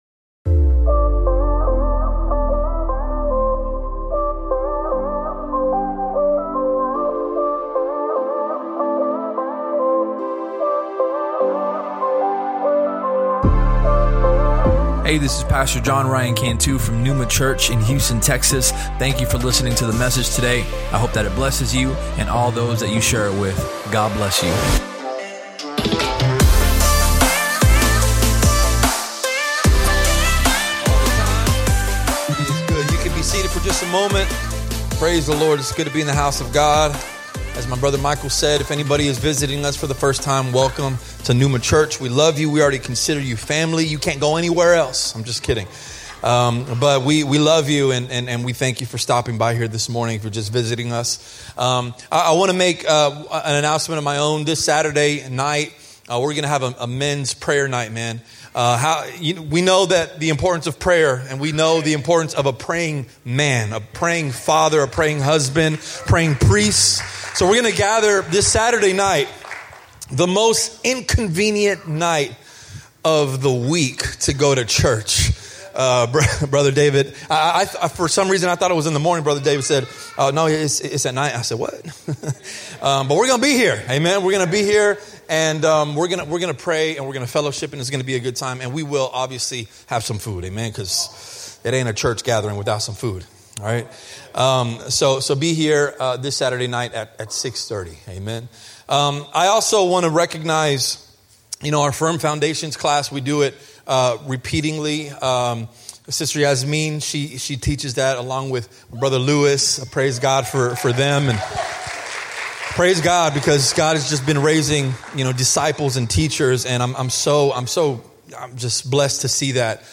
Sermon Topics: Correction, Humility, Forgiveness If you enjoyed the podcast, please subscribe and share it with your friends on social media.